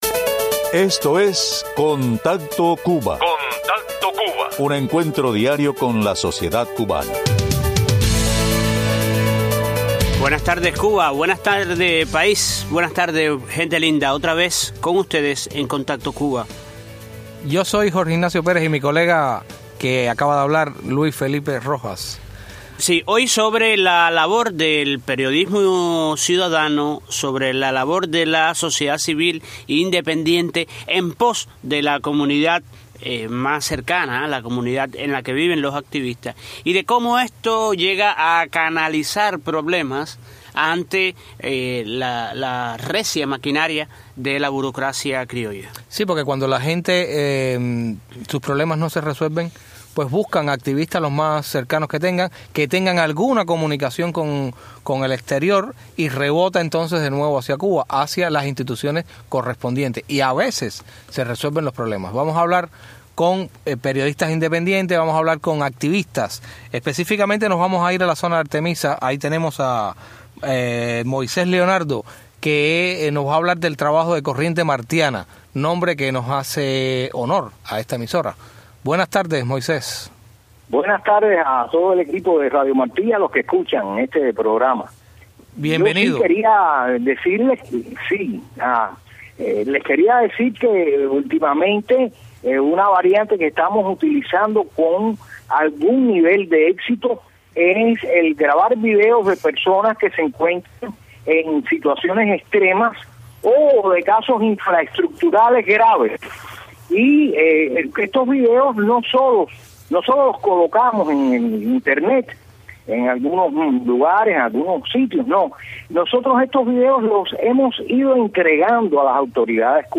Escuche aquí el testimonio de activistas y reporteros ciudadanos que han hecho denunc ias y presentado quejas ante las autoridades en la isla para ayudar a los ciudadanos que se les acercan con estas dificultades.